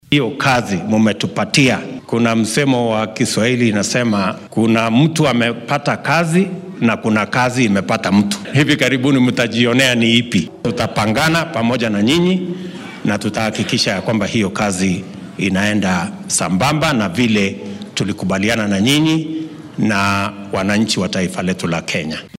Xilli uu shalay dadweynaha kula hadlay fagaaraha Nanyuki Stadium ee ismaamulka Laikipia ,ayuu hoggaamiyaha wadanka xusay inuu diiradda saari doono xallinta caqabadaha sida tooska ah u taabanaya nolol maalmeedka kenyaanka. William Ruto ayaa sheegay in marnaba uunan u dulqaadan doonin in saraakiisha dowladda aynan shacabka ugu adeegin sida laga doonaya.